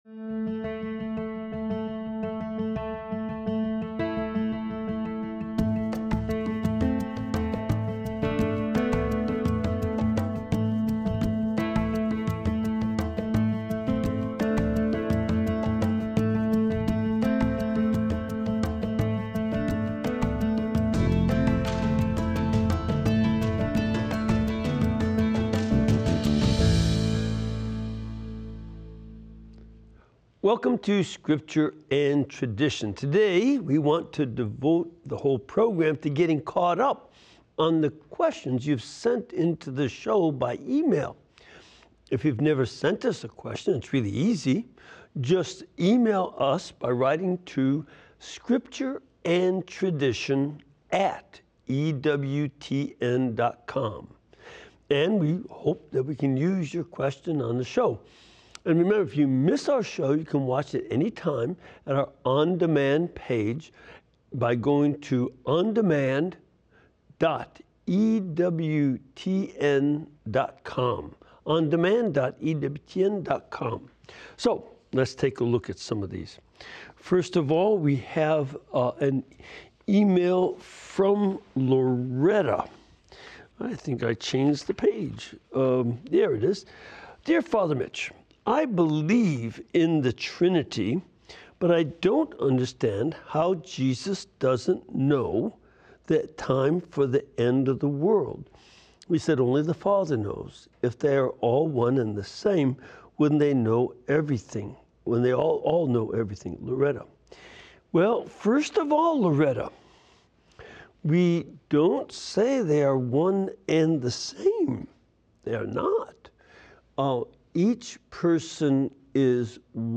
during this weekly live program